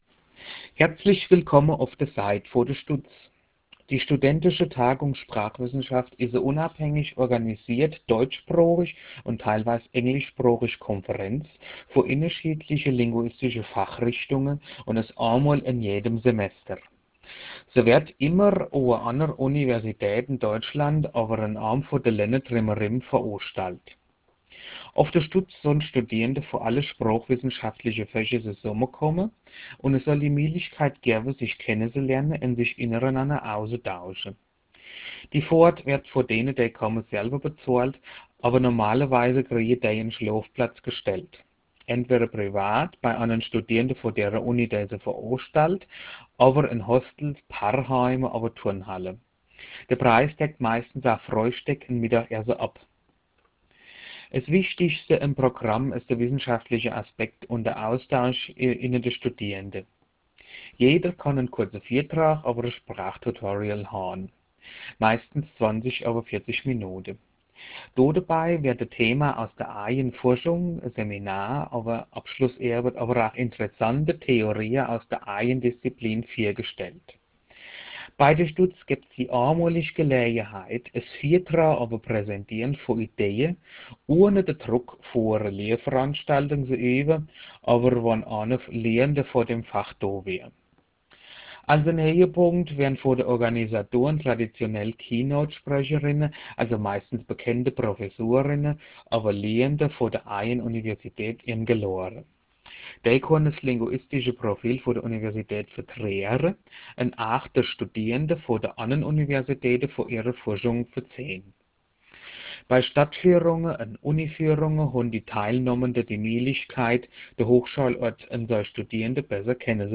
Die StuTS auf Hessisch
Hessisch_-_Die_StuTS.wav